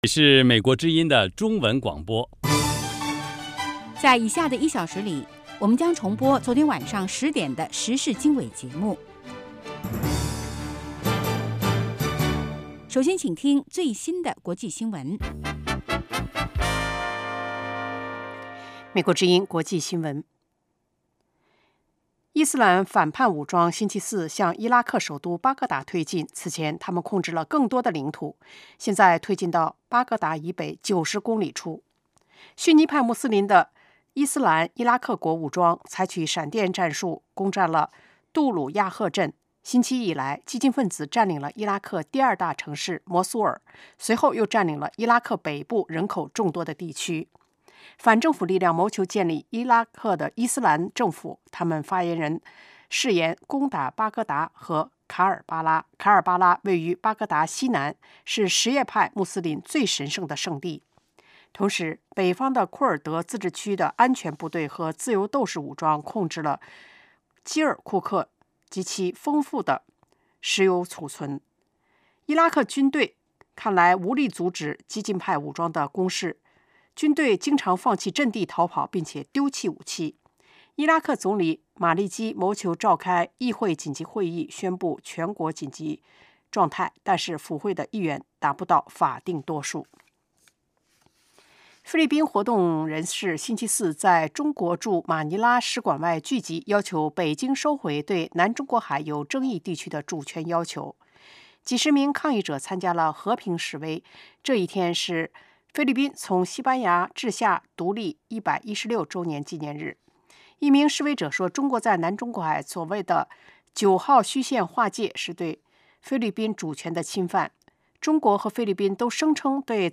周一至周五：国际新闻 时事经纬(重播) 周六：时事经纬 听众热线 (重播) 北京时间: 上午8点 格林威治标准时间: 0000 节目长度 : 60 收听: mp3